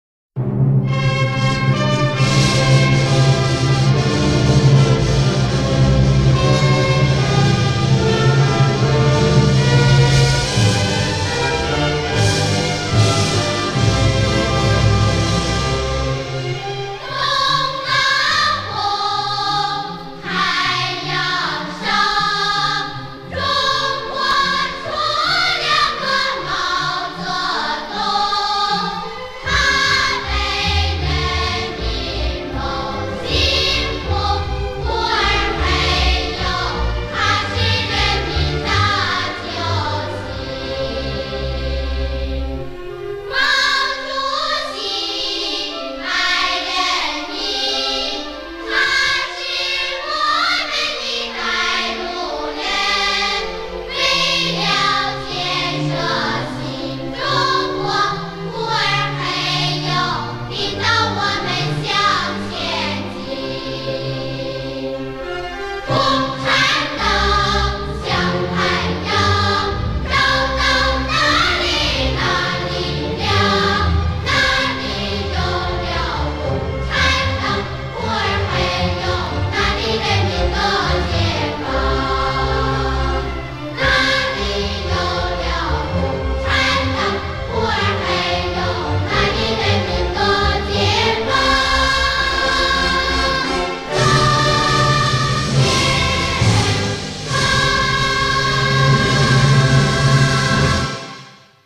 [30/4/2010]童声合唱《东方红》